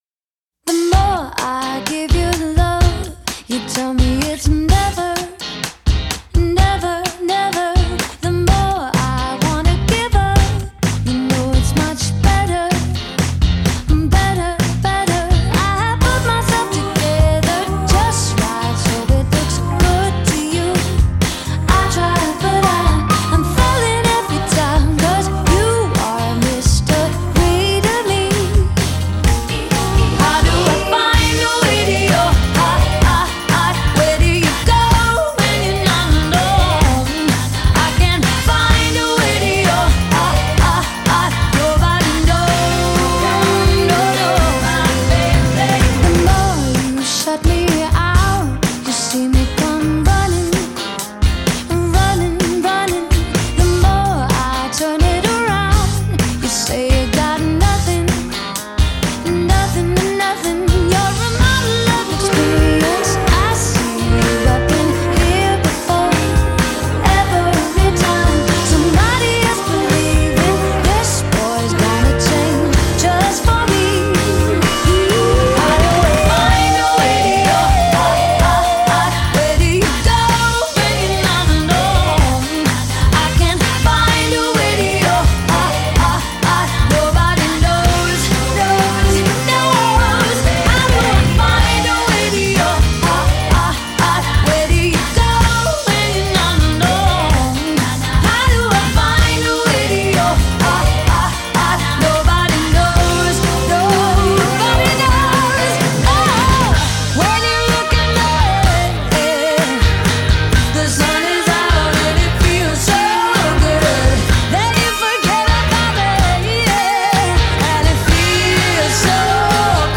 Genre: pop, female vocalists, singer-songwriter